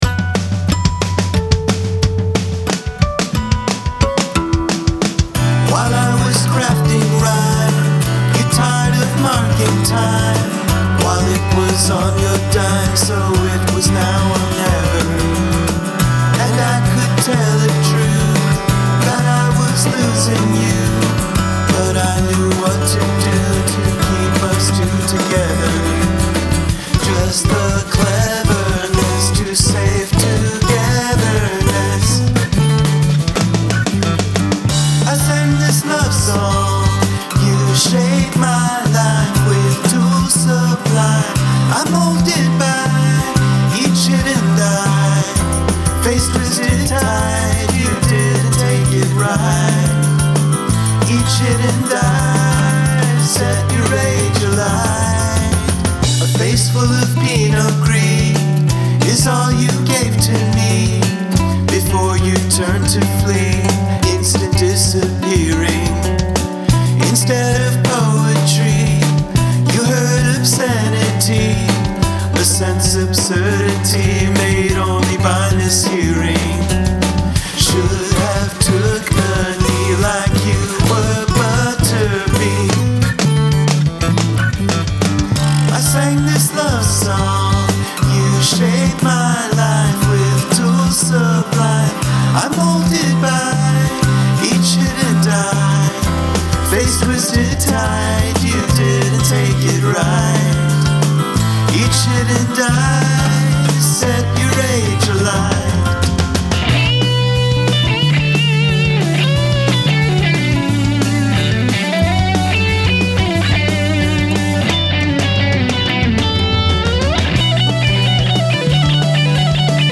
Good energy and singing. I like all the guitar sounds, too.
Yes you get a point for the vibraslap.